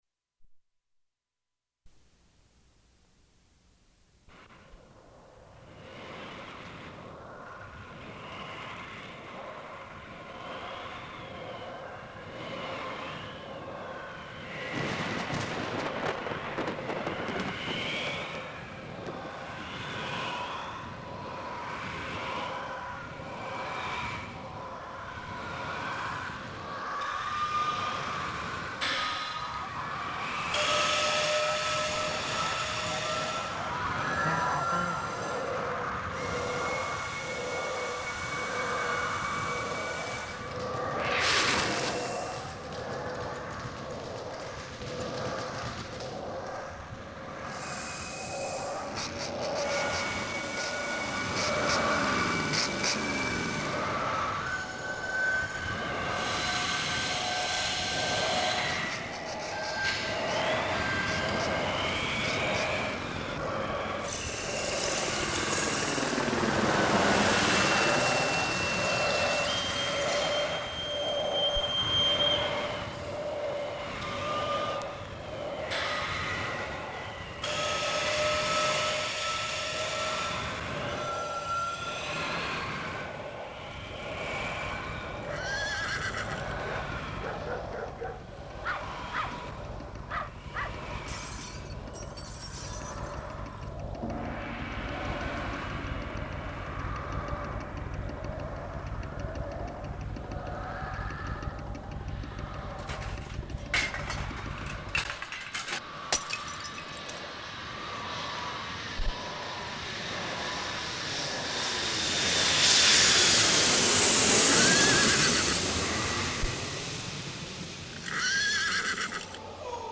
Gorgeous Fever, 1994, Chicago, Randolph Street Gallery, multimedia performance, "Anna Eva Fay" audio for performance
Sound Design
Cassette